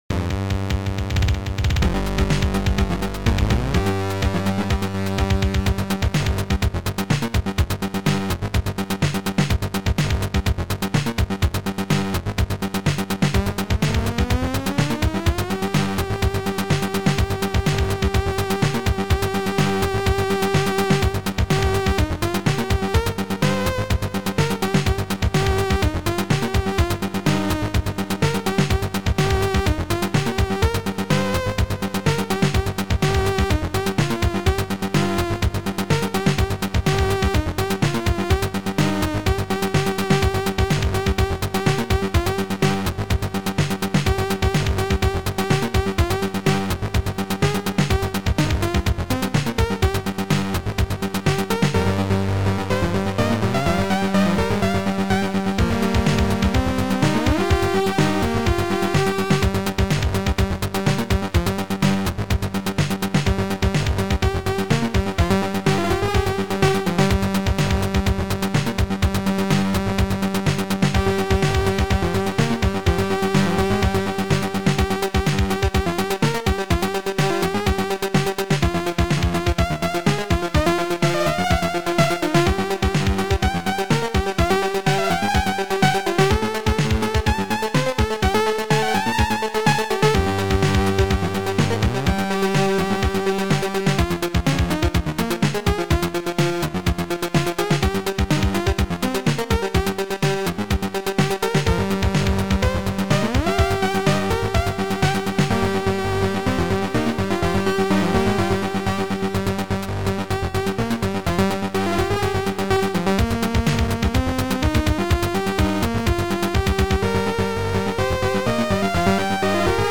SidMon II Module
2 channels